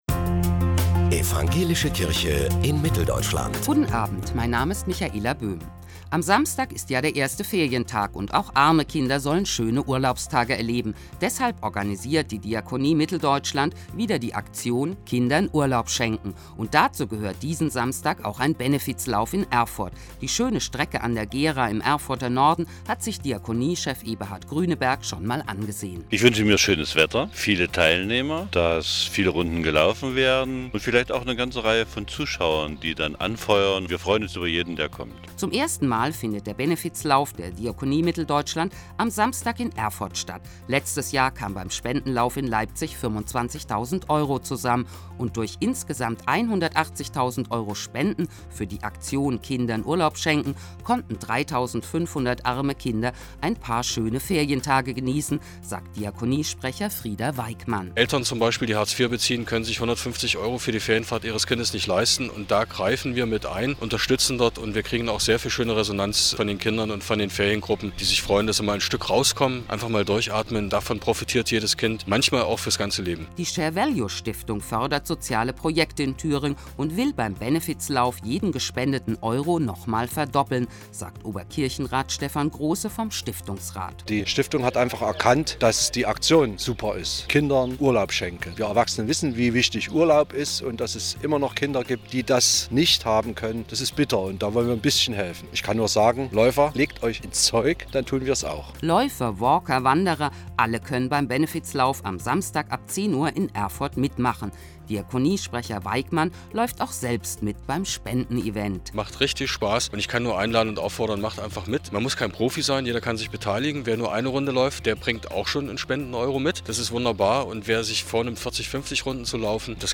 Hörfunkbeitrag vom 15. Juli 2014